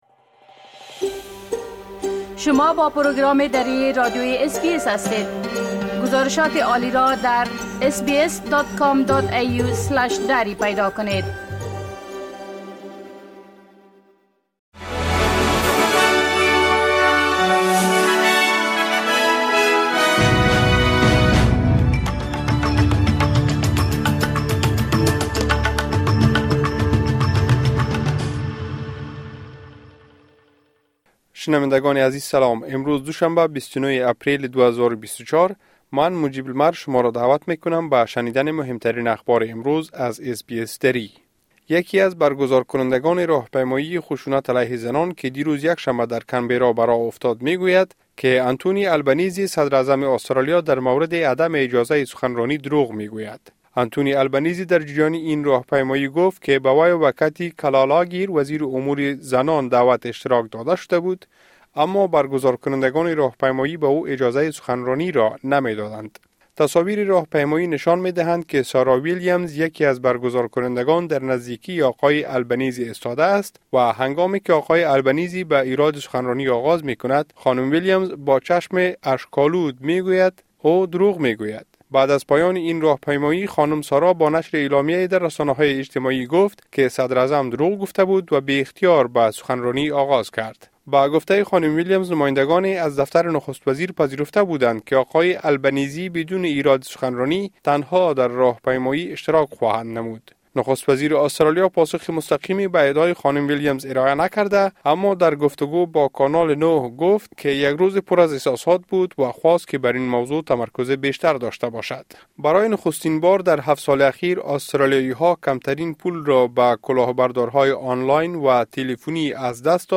خلاصۀ مهمترين اخبار روز از بخش درى راديوى اس بى اس|۲۹ اپریل